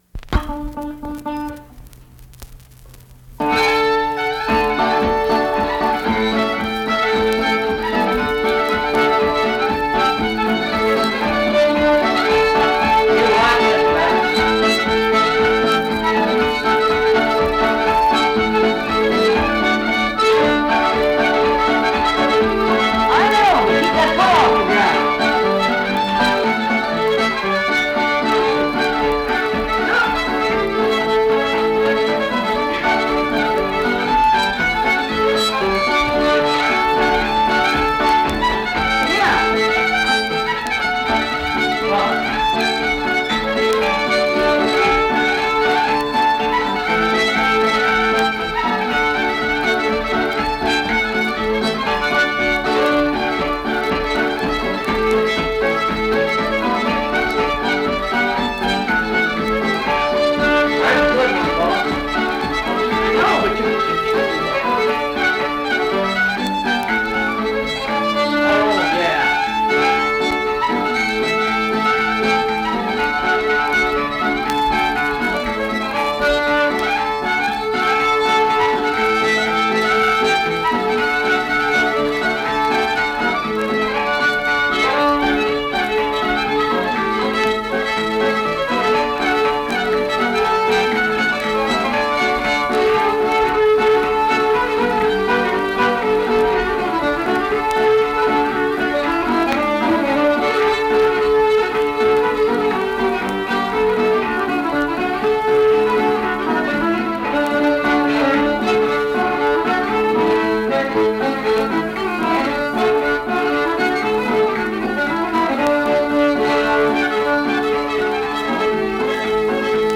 Accompanied guitar and unaccompanied fiddle music performance
Instrumental Music
Fiddle, Guitar
Mill Point (W. Va.), Pocahontas County (W. Va.)